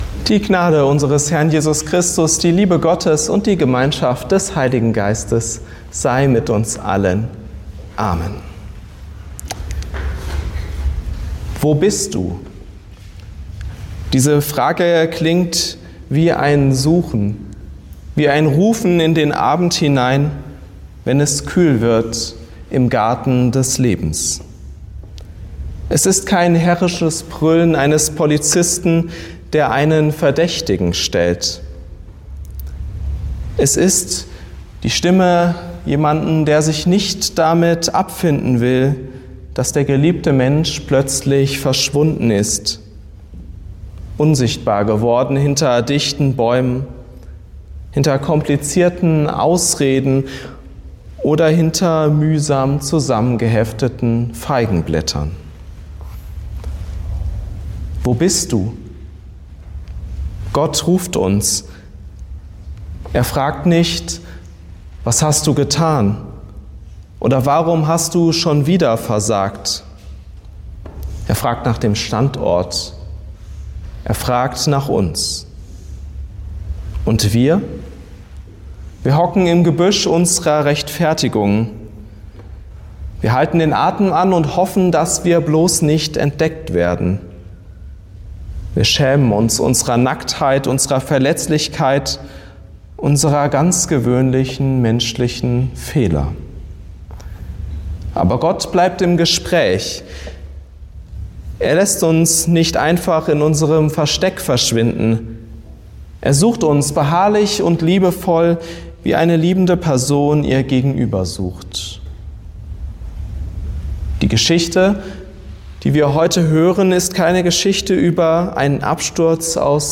Hörpredigten